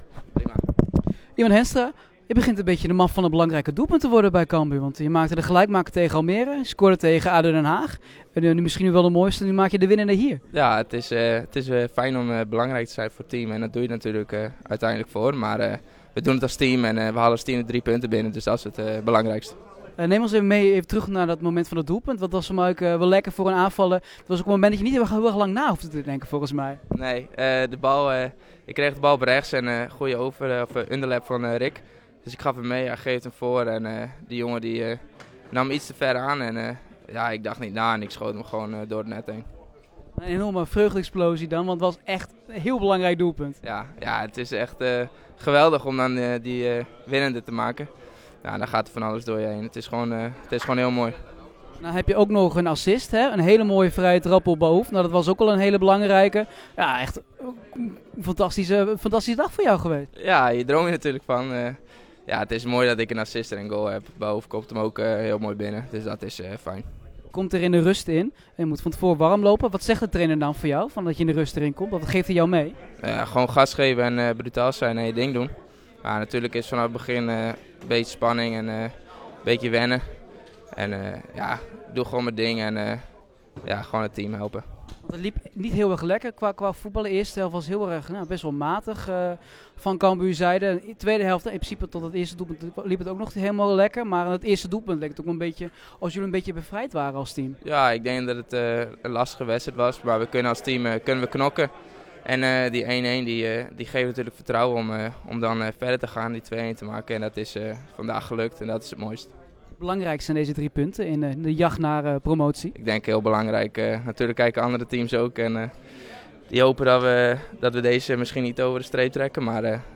Wij spraken met hem en de trainer, die zijn ploeg een volgende stap ziet zetten richting promotie naar de Eredivisie.